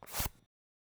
paddle-final.wav